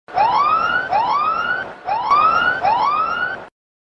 no ball Meme Sound Effect